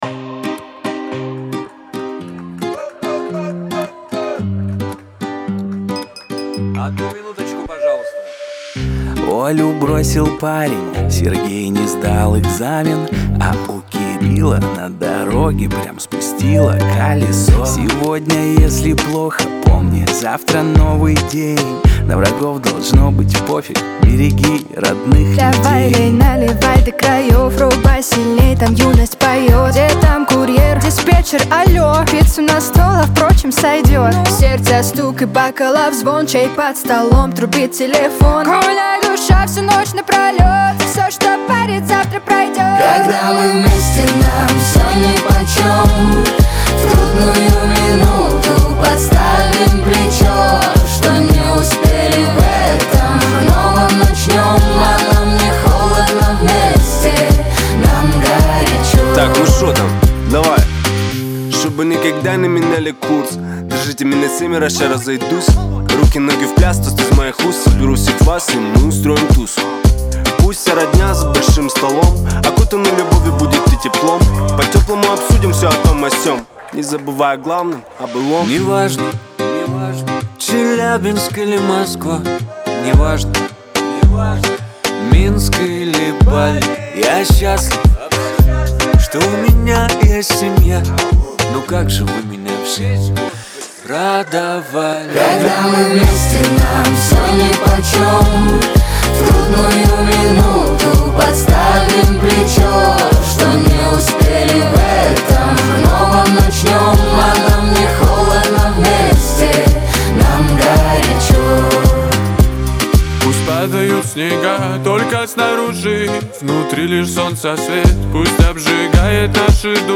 pop , ХАУС-РЭП
дуэт , диско